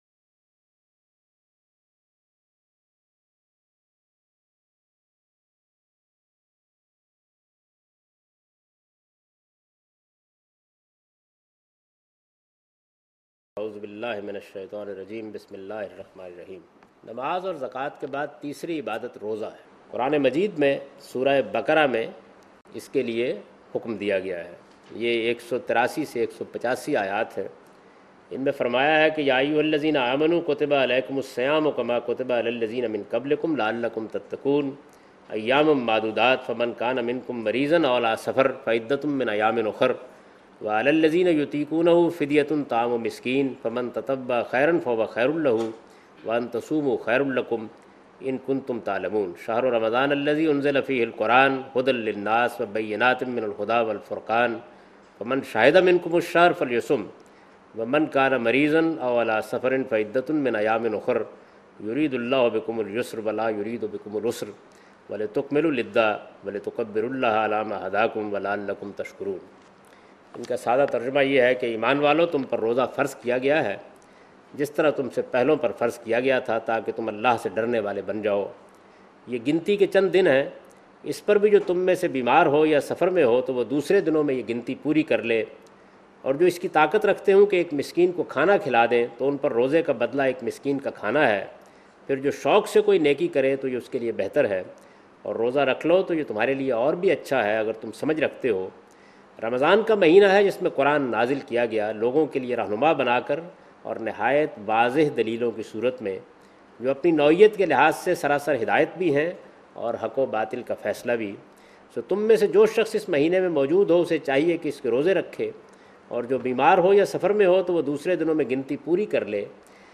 Qanoon-e-Ibadaat Roza Lecture 180
Javed Ahmad Ghamidi teaches his book Meezan Chapter "Qanoon-e-Ibadaat Roza"